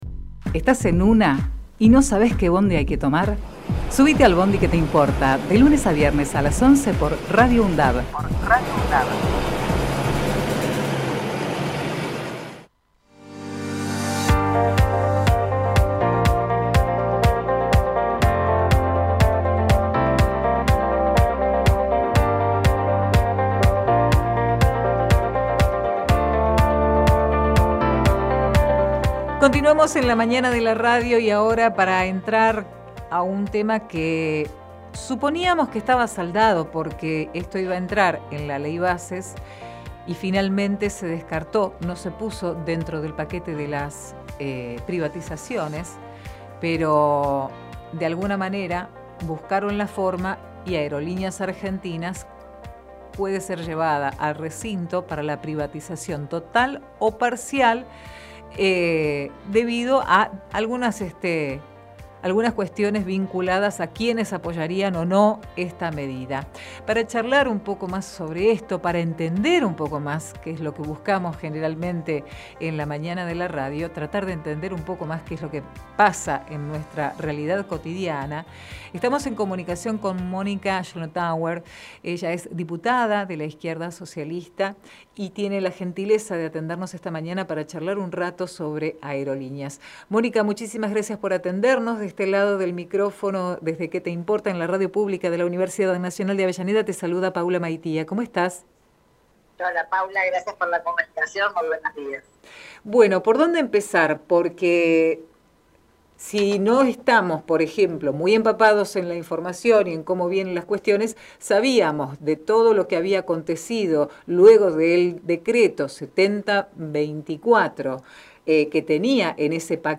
QUÉ TE IMPORTA - MÓNICA SCHLOTTHAUER Texto de la nota: Compartimos la entrevista realizada en "Que te importa?!" a Mónica Schlotthauer, Diputada Nacional Izquierda Socialista/FIT Unidad, en el marco de la posible privatización de Aerolíneas Argentinas.